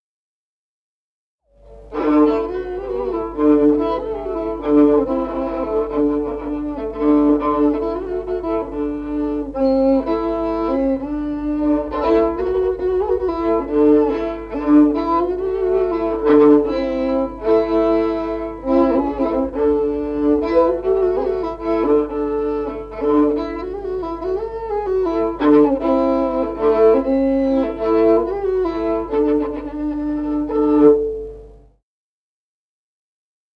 Музыкальный фольклор села Мишковка «Под царские вороты», свадебная, партия 2-й скрипки.